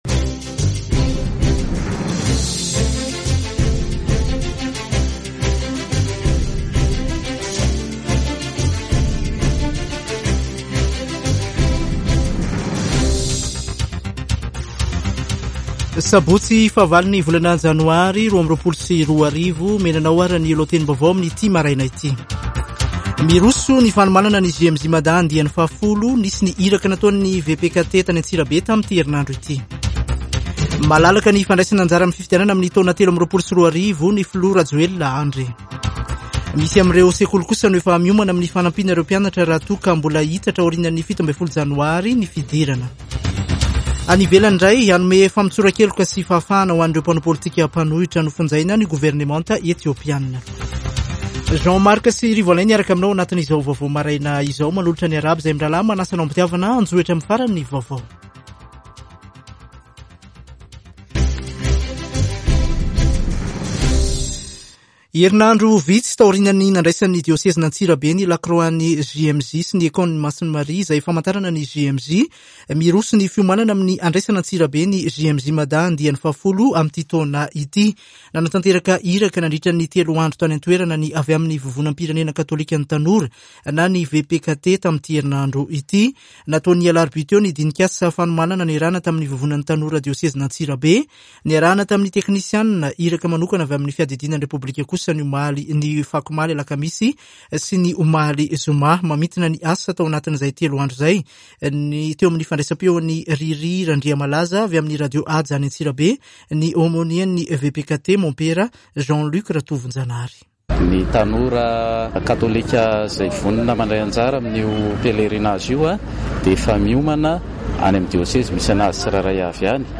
[Vaovao maraina] Sabotsy 08 janoary 2022